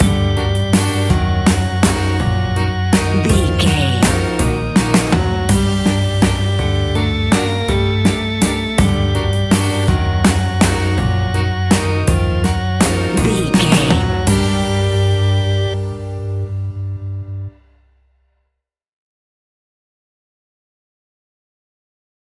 Aeolian/Minor
scary
ominous
eerie
piano
synthesiser
drums
electric organ
strings
spooky
horror music